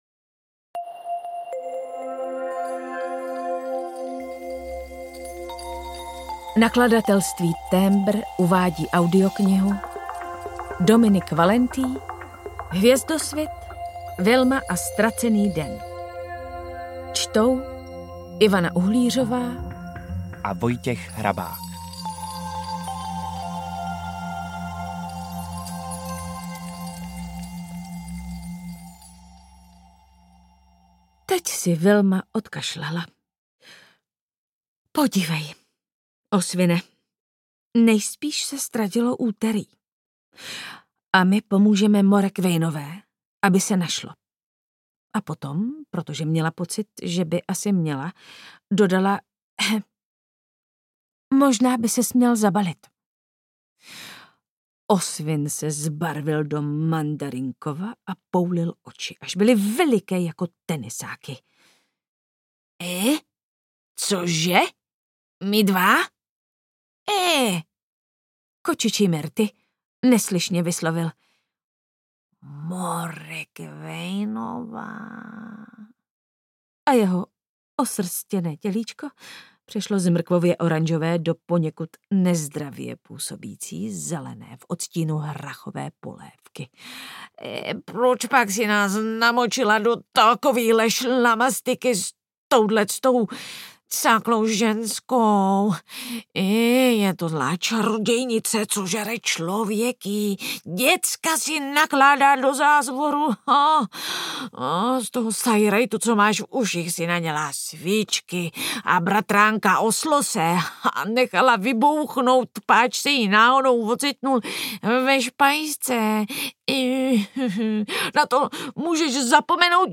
Ukázka z knihy
vilma-a-ztraceny-den-audiokniha